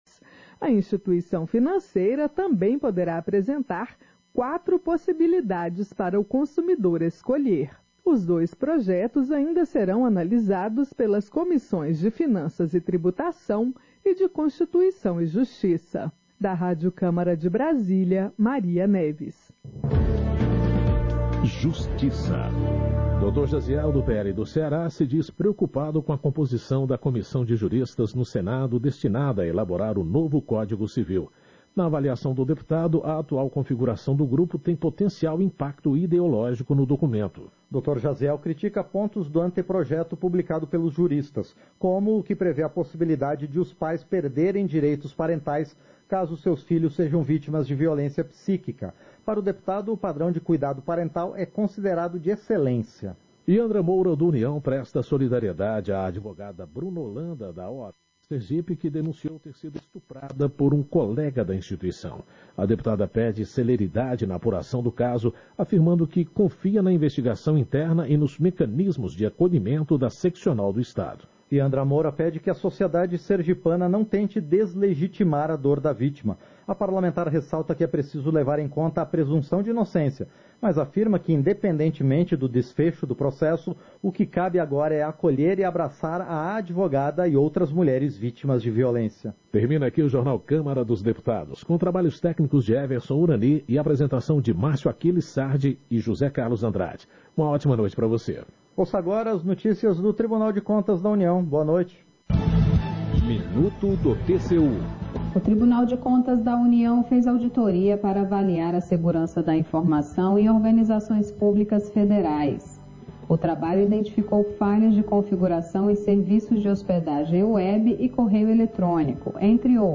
Sessão ordinária 09/2024